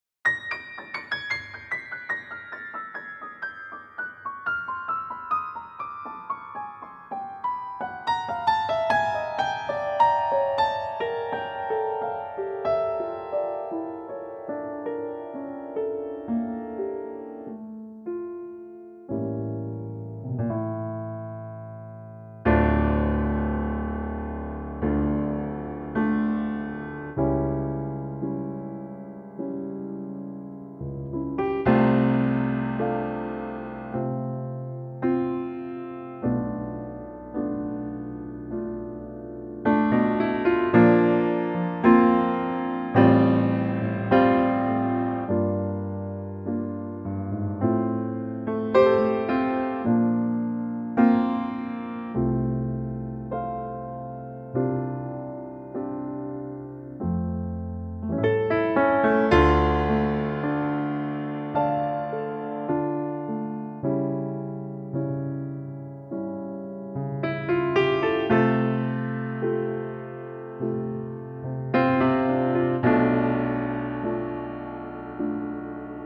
key Dm
-Unique Backing Track Downloads